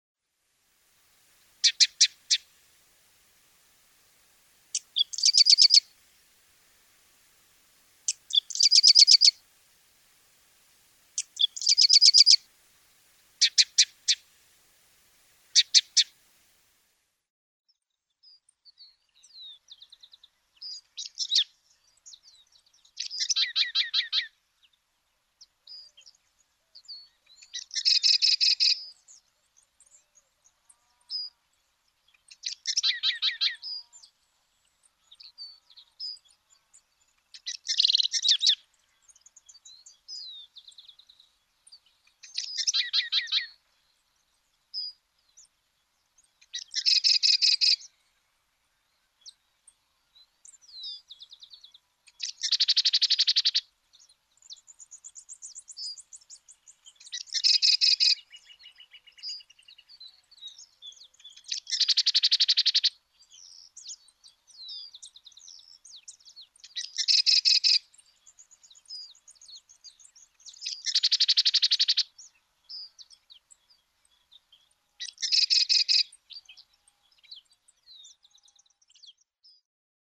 Cistothorus platensis - Ratonera aperdizada
Cistothorus platensis.mp3